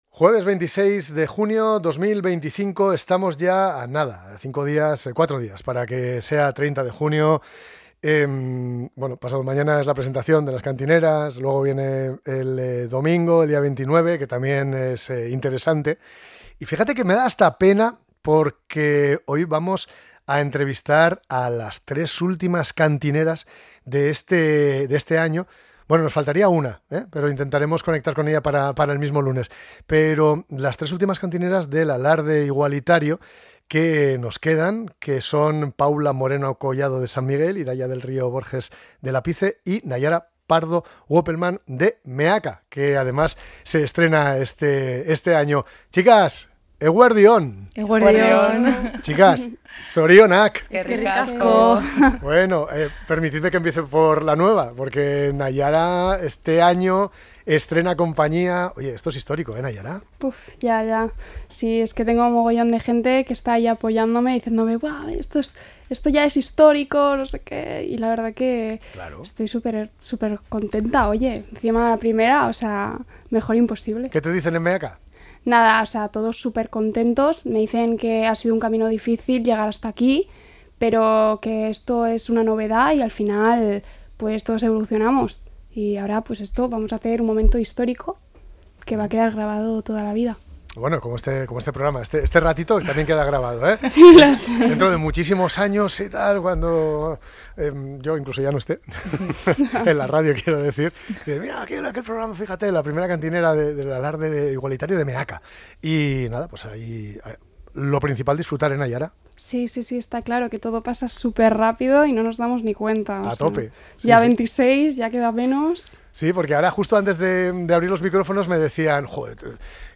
ENTREVISTA CANTINERAS PÚBLICO MEAKA SAN MIGUEL Y LAPICE